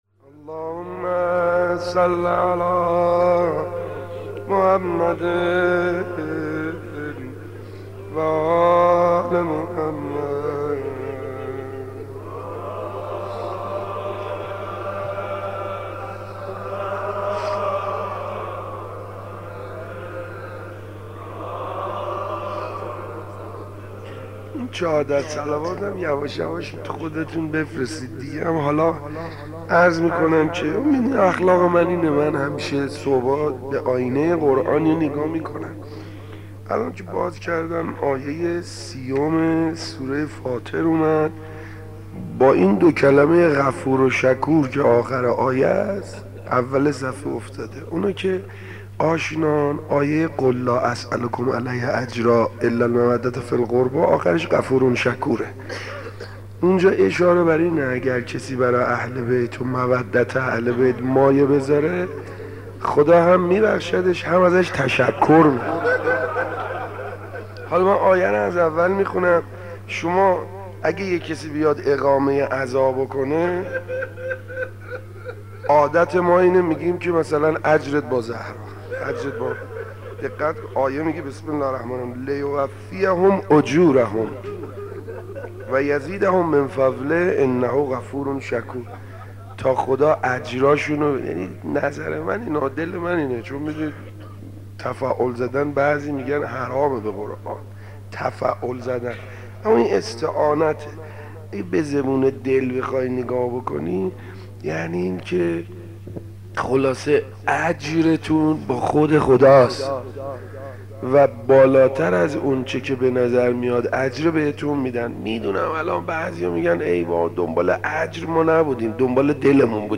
مداح
مناسبت : شب اول محرم
مداح : سعید حدادیان